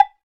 Index of /90_sSampleCDs/NorthStar - Global Instruments VOL-2/CMB_CwBell+Agogo/CMB_CwBell+Agogo